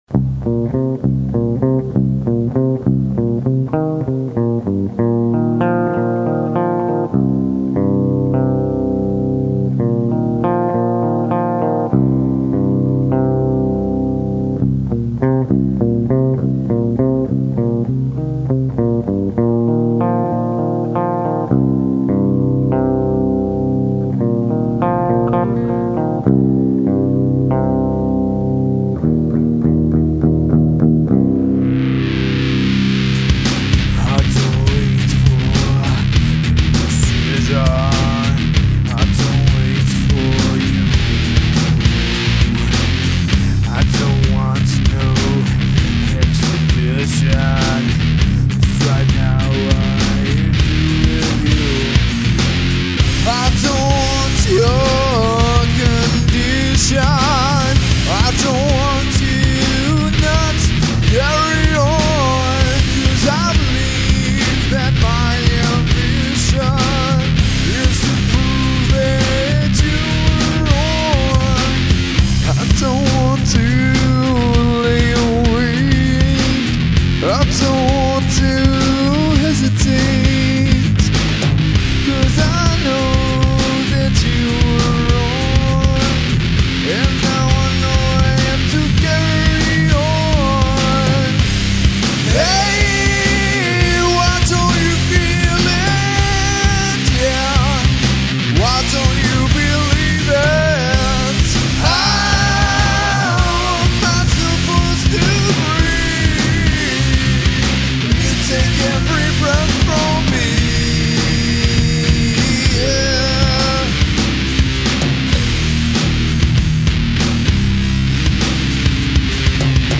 Rock
need more distorsion! ^_^
I thought the distortion already was plenty good *^_^*